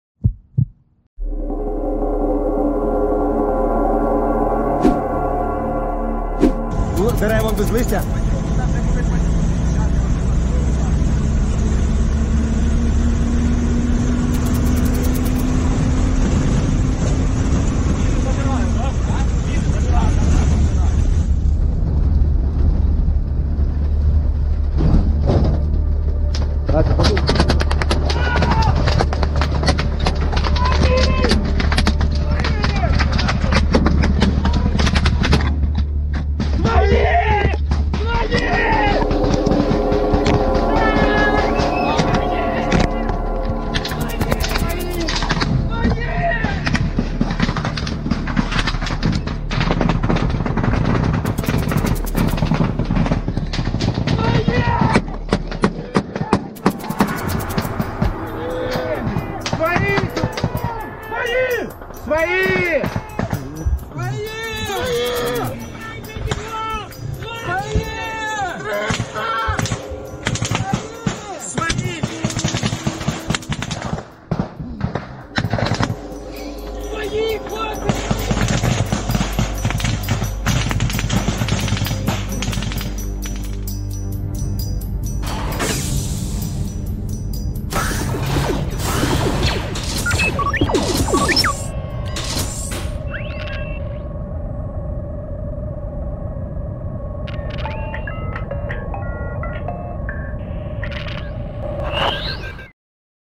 Well... can say one thing for certain, The ambush party wasn't stingy with the bullets, thats for sure.